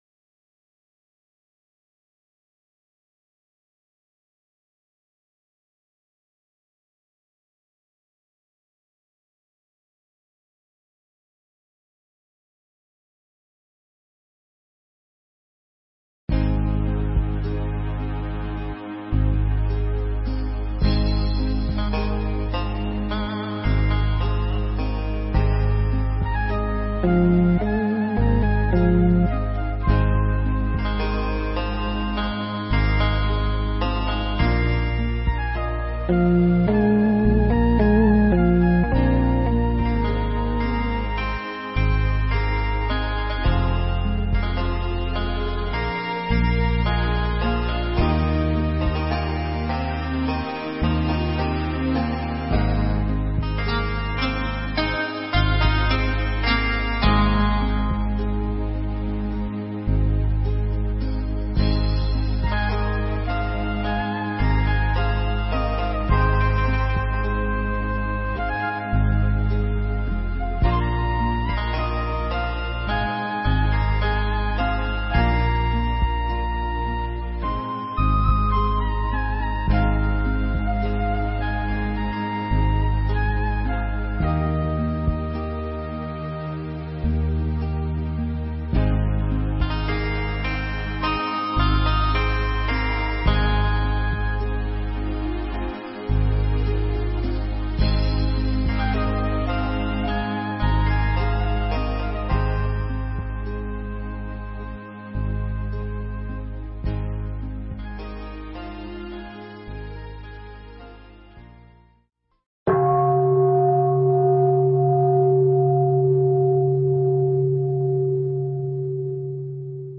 Nghe Mp3 thuyết pháp Hướng Đến Ánh Sáng